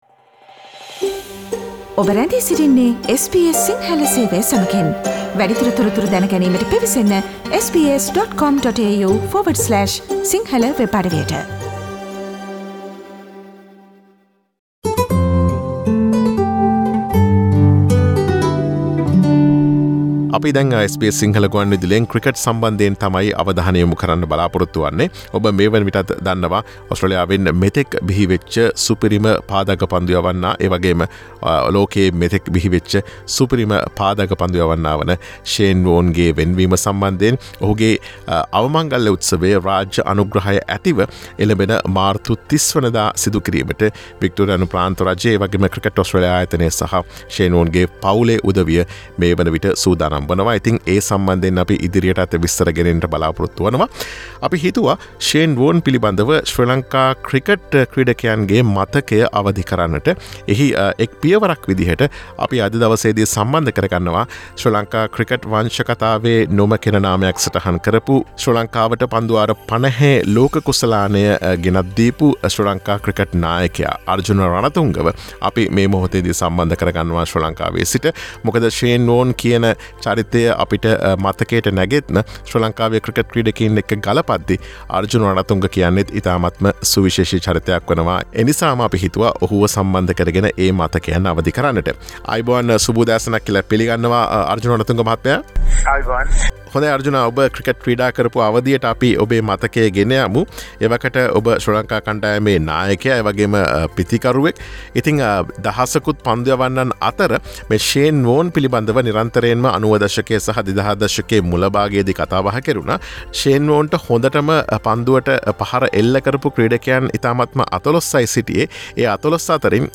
ශ්‍රී ලංකා ක්‍රිකට් කණ්ඩායමේ හිටපු නායක අර්ජුන රණතුංග ලොවෙන් සමුගත් Shane Warne පිළිබඳ සිය මතකයන් අවධි කරමින් SBS සිංහල ගුවන් විදුලිය සමග සිදුකළ සාකච්ඡාවට සවන්දෙන්න.